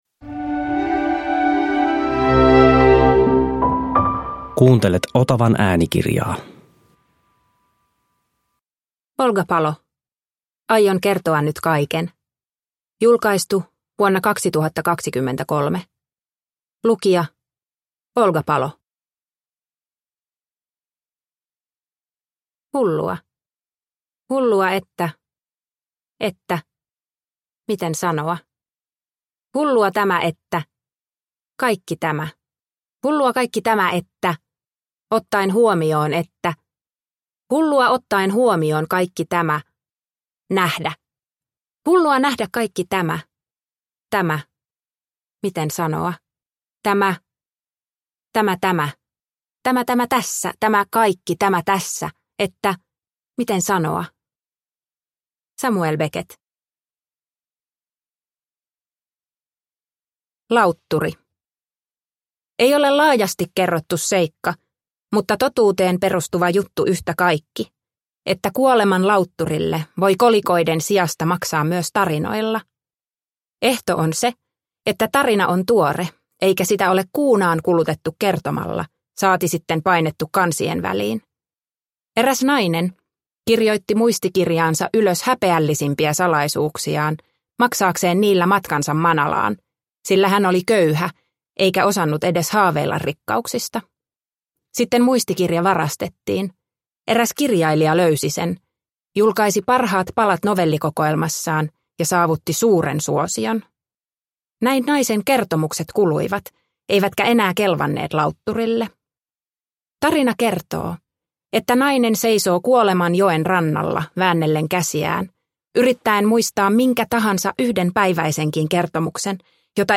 Aion kertoa nyt kaiken – Ljudbok – Laddas ner